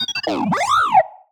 happy6.wav